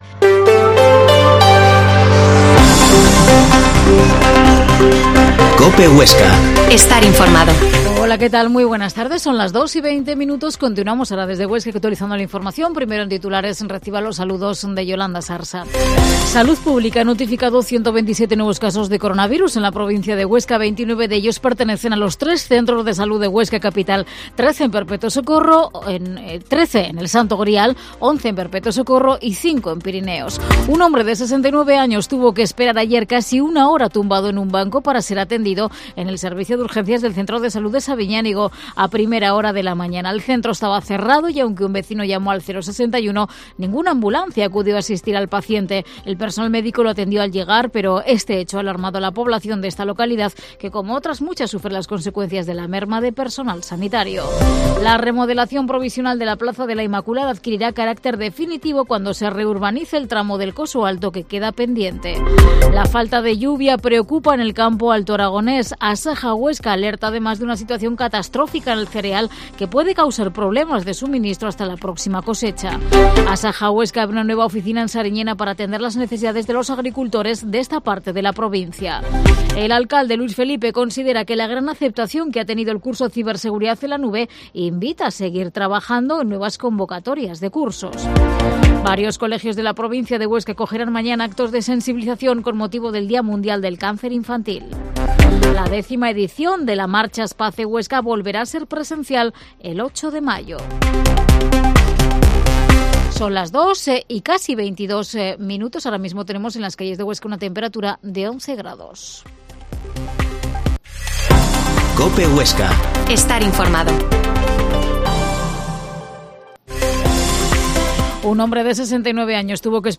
Informativo Mediodía en Huesca